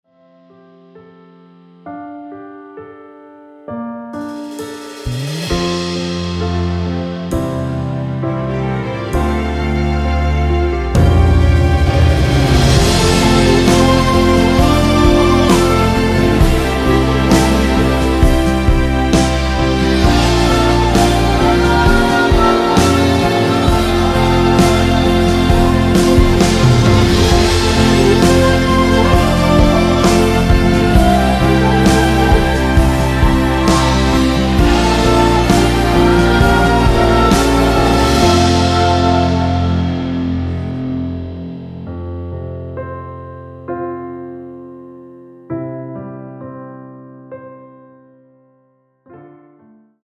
미리듣기에 나오는 부분이 코러스로 추가되었습니다.
(다른 부분은 원곡 자체에 코러스가 없습니다.)
원키에서(-2)내린 코러스 포함된 MR입니다.
앞부분30초, 뒷부분30초씩 편집해서 올려 드리고 있습니다.